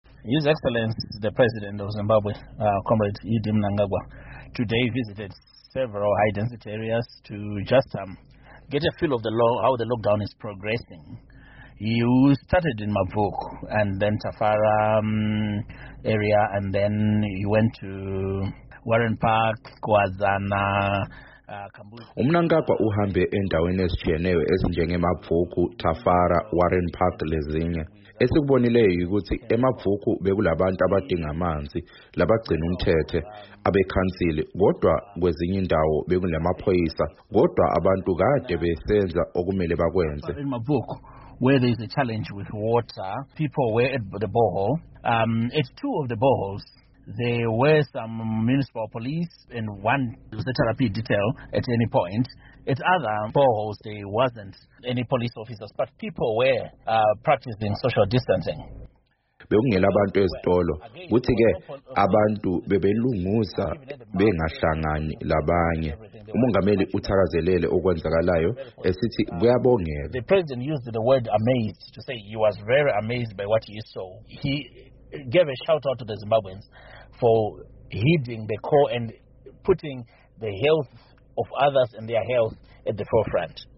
Ingxoxo Esiyenze loMnu. Nick Mangwana